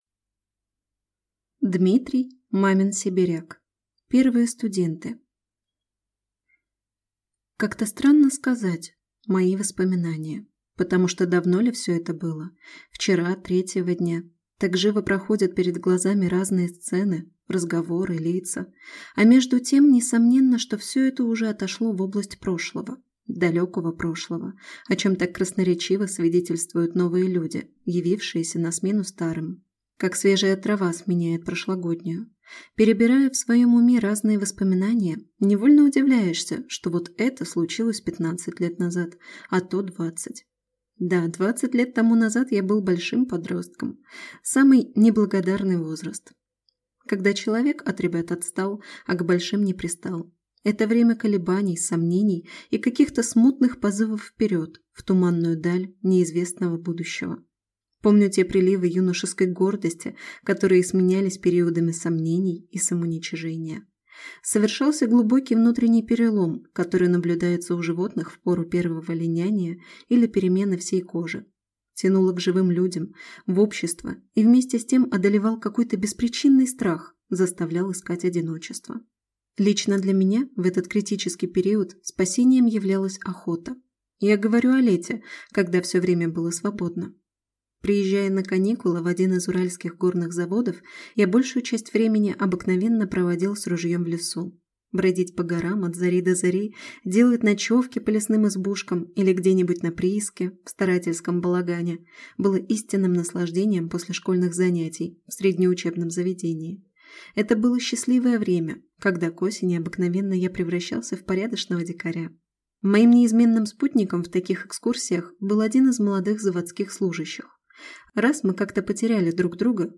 Аудиокнига Первые студенты | Библиотека аудиокниг